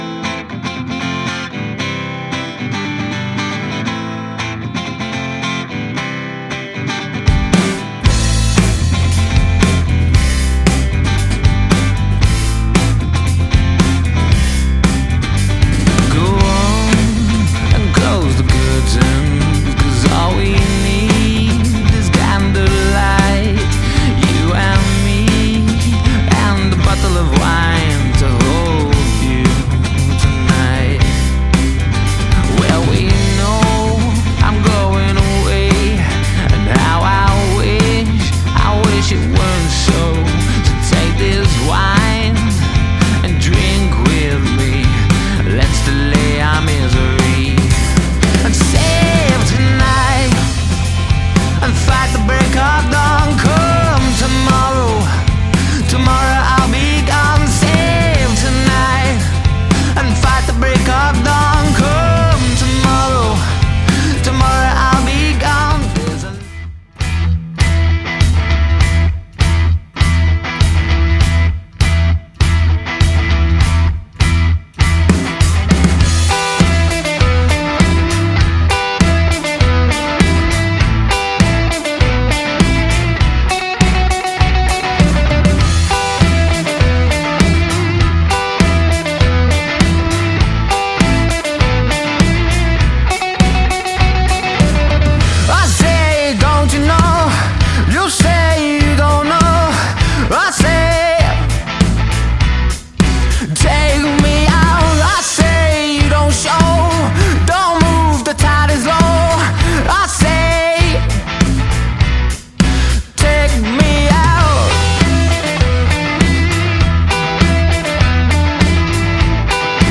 3-piece Male Fronted Rock/Pop Band
• Pop, rock and indie anthems to keep the dance floor full!
Male Vocals/Guitar, Bass, Drums